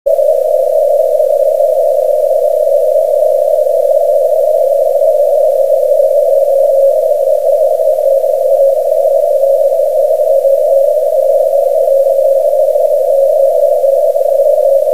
・他も聞いてると、なんとPJ2Tが鮮やかに聞こえる!　こっちもQSBを伴いつつ一時間半ほど聞こえてたが、残念ながらCEと同様全くかすりもしなかった。